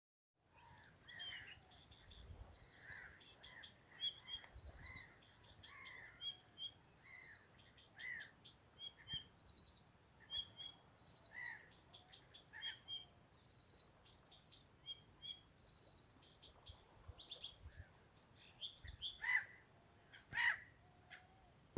садовая камышевка, Acrocephalus dumetorum
Administratīvā teritorijaEngures novads
Примечания/dzied, bet ticams ka neligzdotājs.